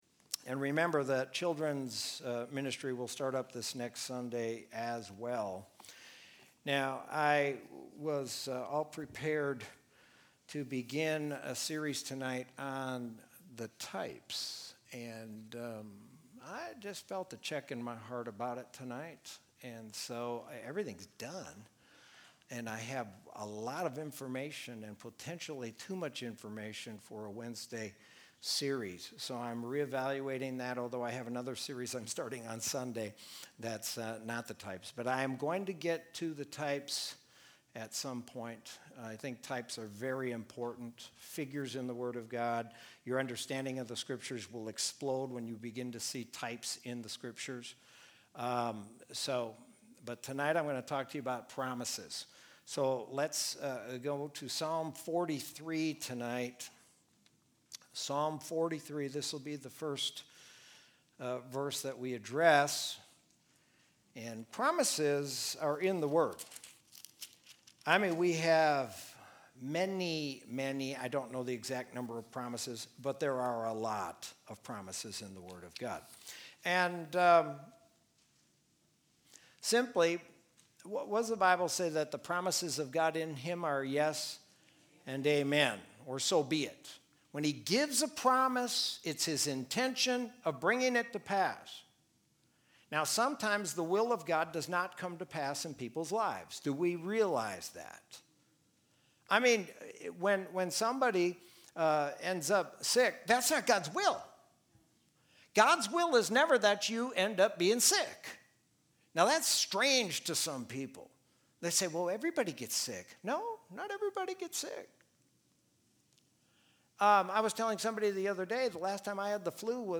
Sermon from Wednesday, June 3, 2020.